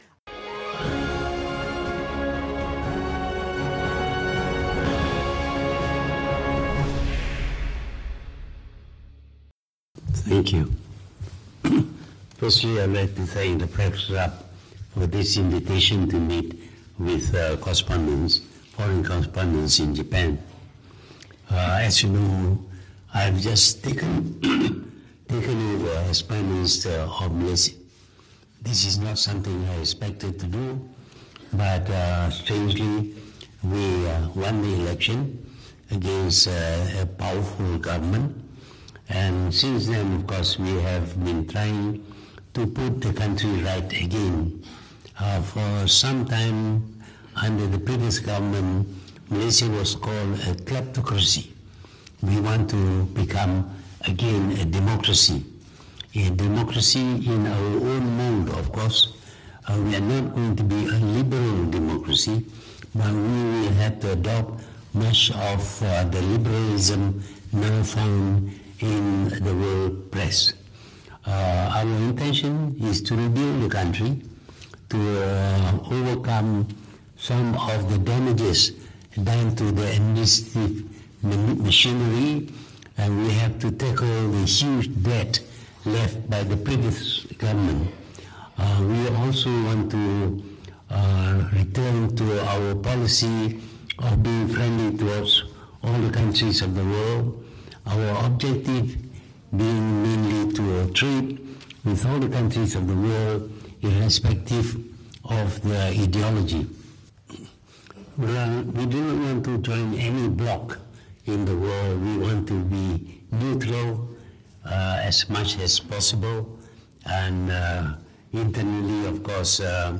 Ikuti sidang media oleh Perdana Menteri, Tun Dr. Mahathir Mohamad bersama Kelab Media Kebangsaan Jepun pasca dialog di Persidangan Antarabangsa Mengenai Masa Depan Asia di Tokyo, Jepun.